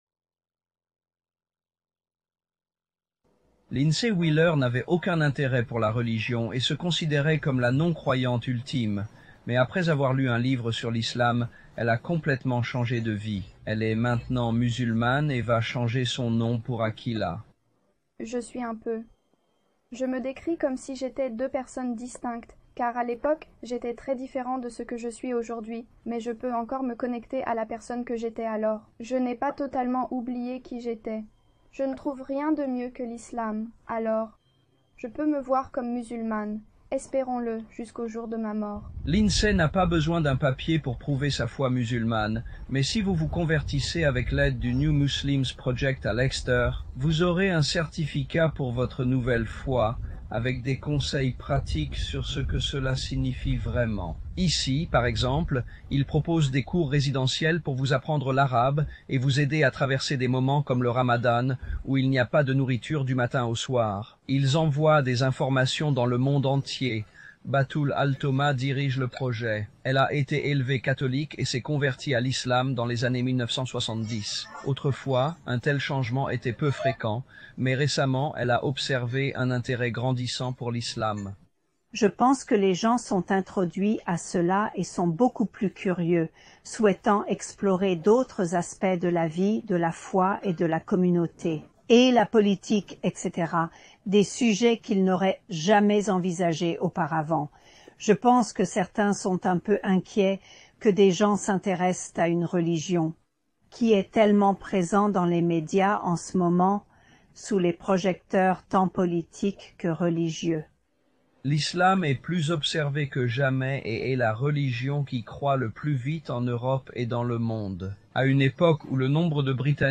Description: Dans ce reportage de 5 News, les journalistes expliquent comment l'Islam est devenu la religion qui croît le plus rapidement au Royaume-Uni et en Europe.